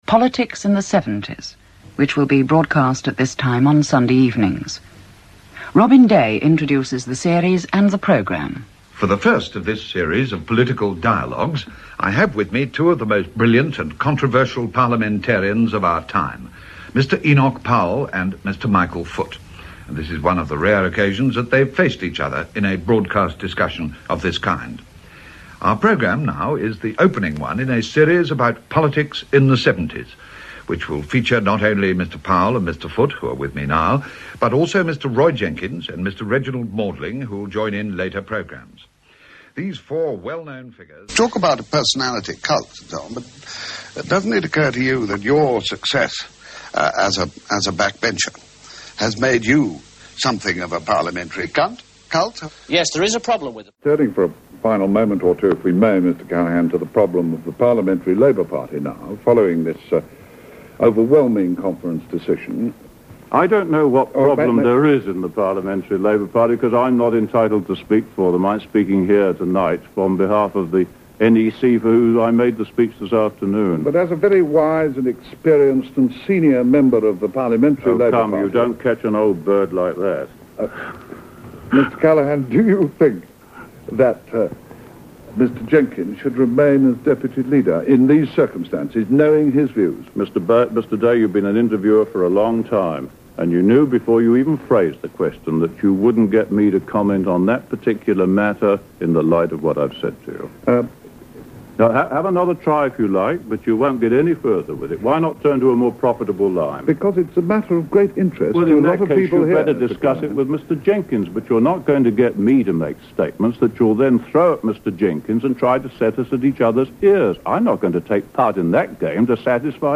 But radio too was also a home for his breathless, distinctive voice.
This audio begins with Radio 4, but I confess it veers into TV sound. Well worth it though, as he commits a common Spoonerism, then harangues the then Prime Minister, James Callaghan.